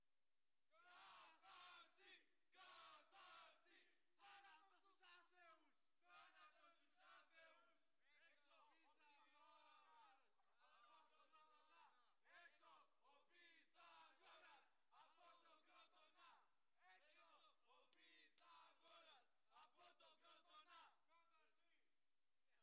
Οι παριστάμενοι στην αίθουσα είναι πιά εκτός ελέγχου και κινούνται σαν παγιδευμένα ζώα φωνάζοντας ΦΩΤΙΑ! ΦΩΤΙΑ! ΦΩΤΙΑ!!!!!!